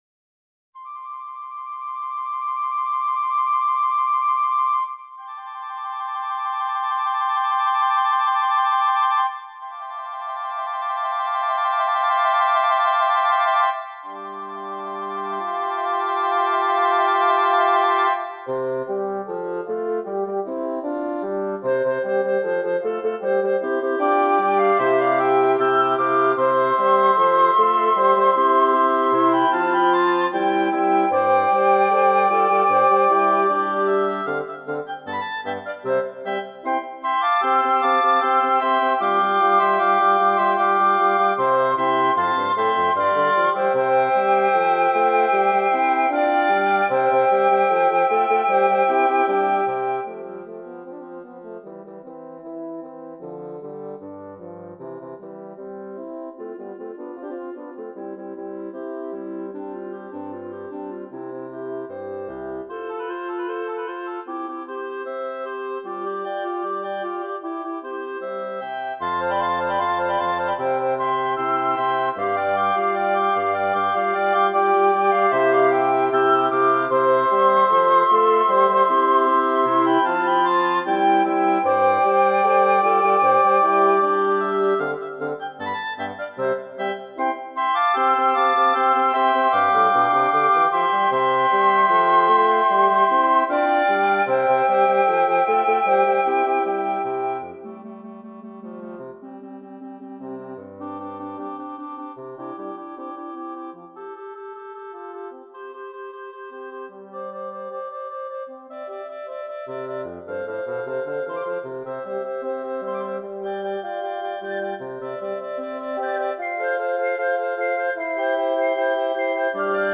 for Woodwind Quintet (2021)
MIDI demo